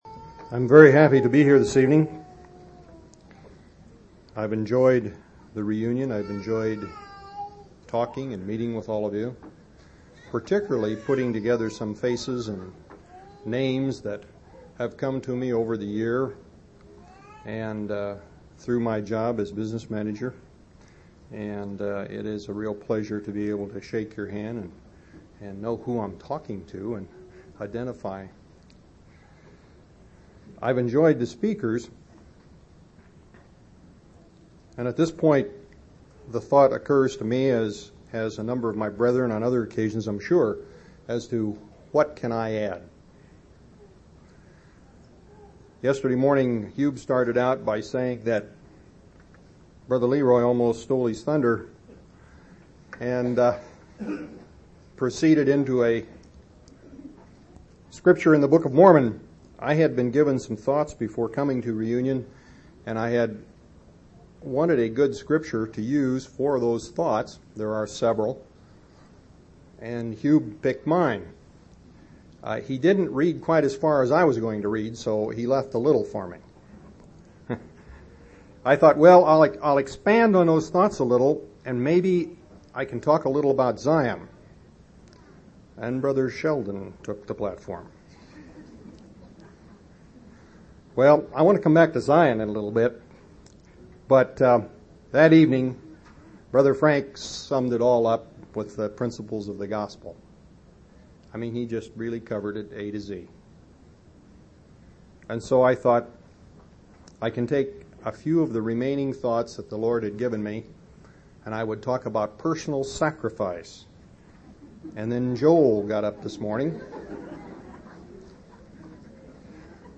8/10/1991 Location: Missouri Reunion Event